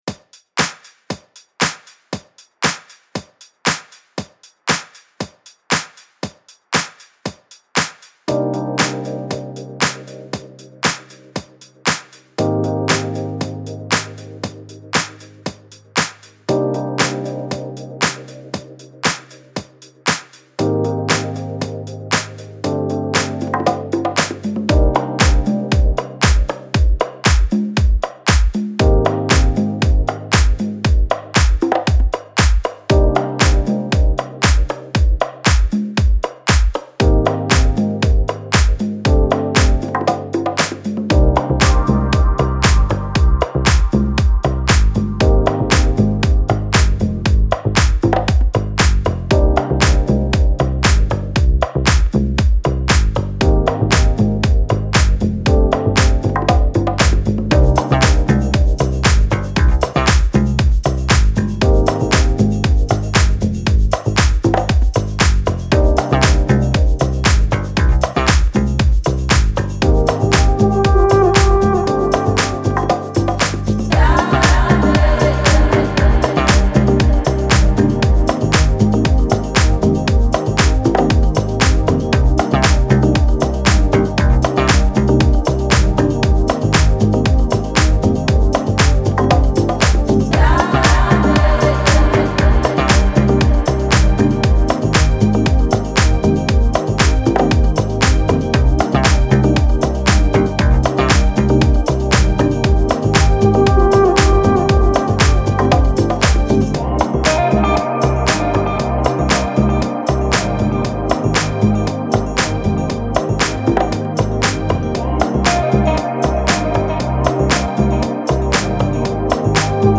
Genre: Chillout, Deep House.